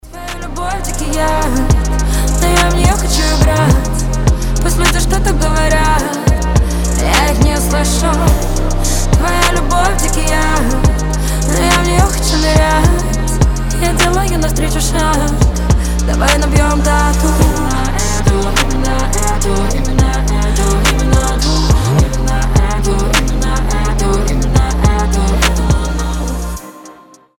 • Качество: 320, Stereo
женский голос
басы